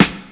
• High Quality Smooth Rap Snare One Shot G Key 543.wav
Royality free acoustic snare sample tuned to the G note. Loudest frequency: 2161Hz
high-quality-smooth-rap-snare-one-shot-g-key-543-olH.wav